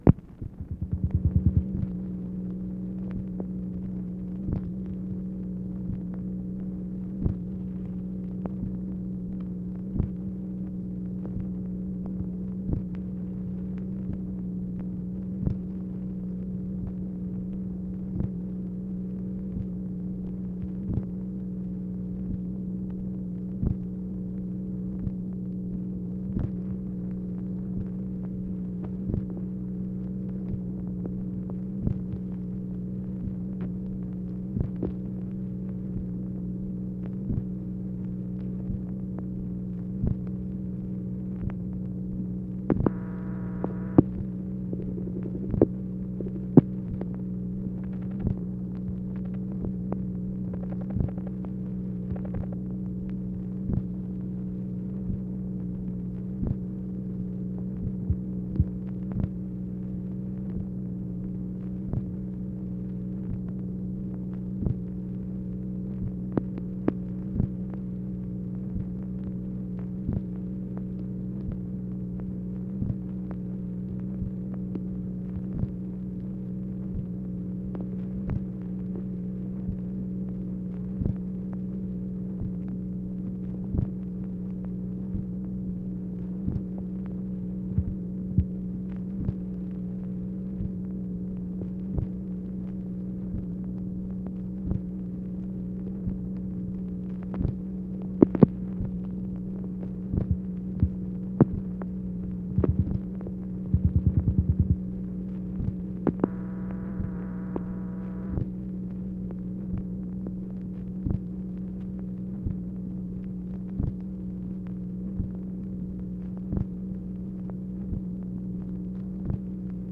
MACHINE NOISE, January 22, 1964
Secret White House Tapes | Lyndon B. Johnson Presidency